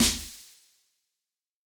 snakeShoot.ogg